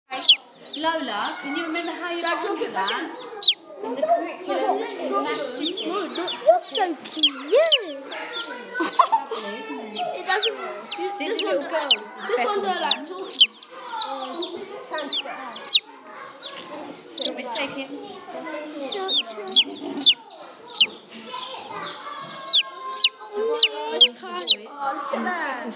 Chirping of Chicks